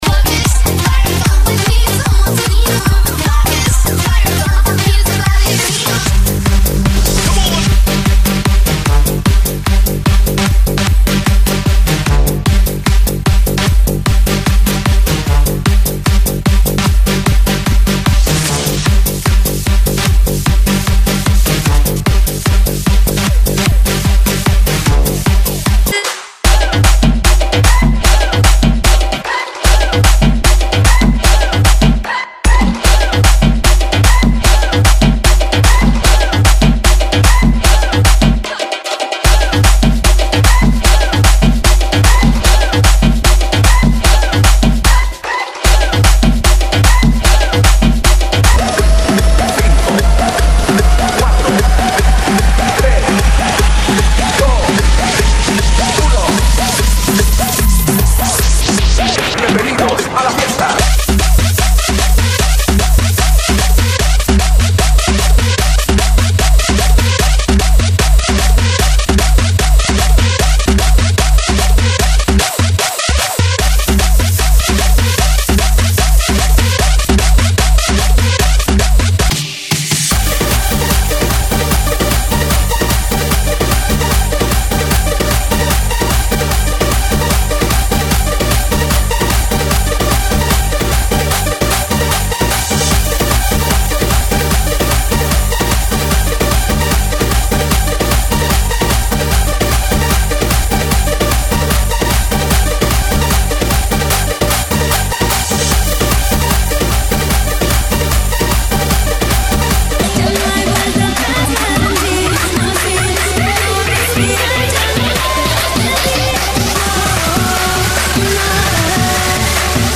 GENERO: ANTRO